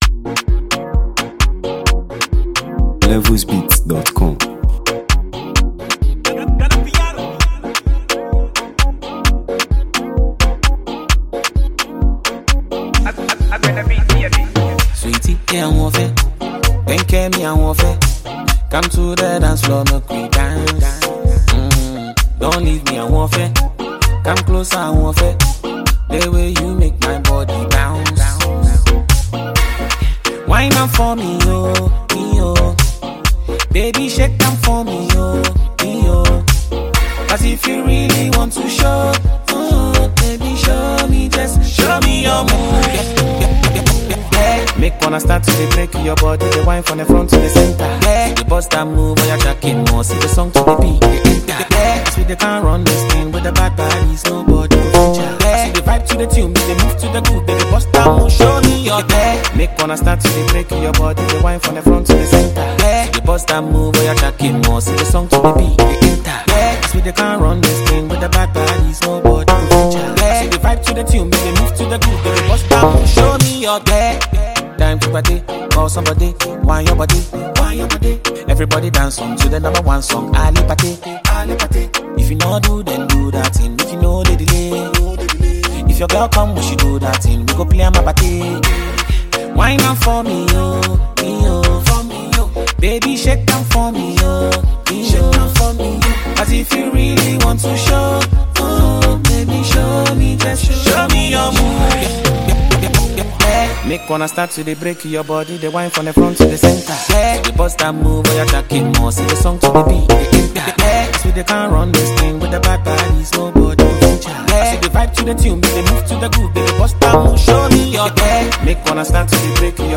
Ghana Music 2025 3:15
earning praise for its addictive rhythm, crisp production